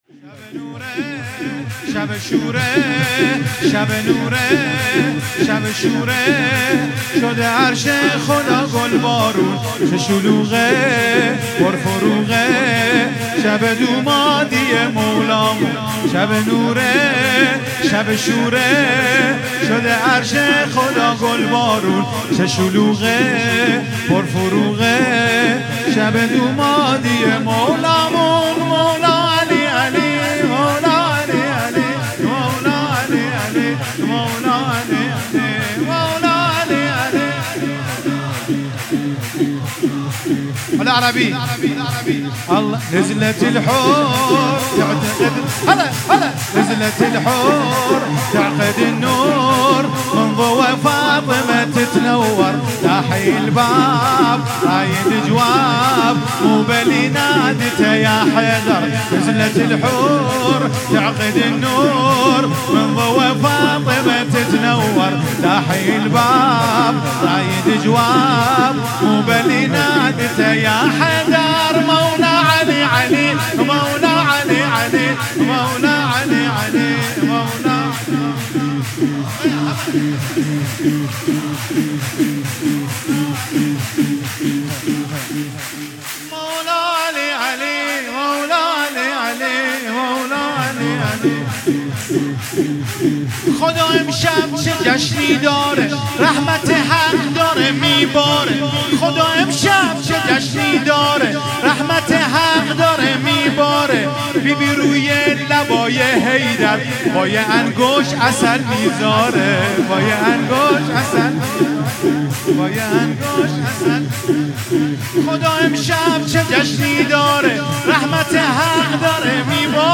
1 شهریور 96 - هیئت ریحانه النبی - شور - شب نور ، شب شور